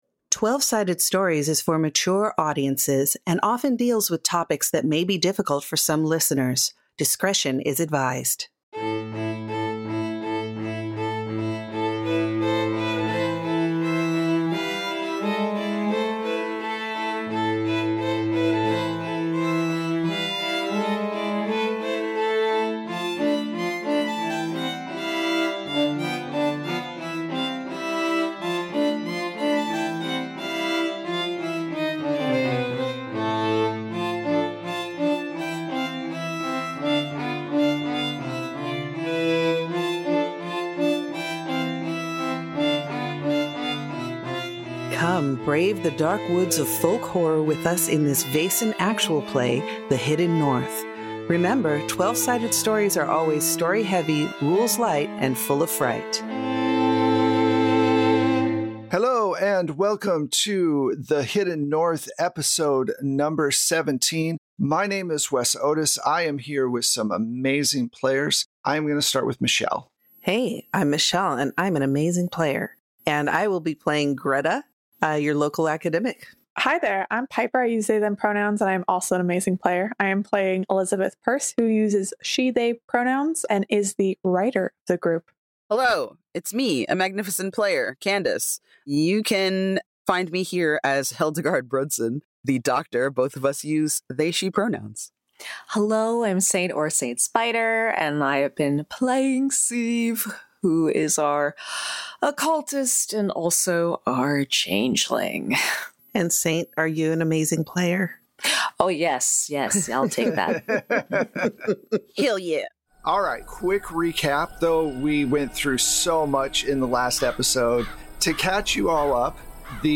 Worlds beyond imagination await you! At Twelve-Sided Stories, we bring tales to life through TTRPGs, with fully produced sound effects and music.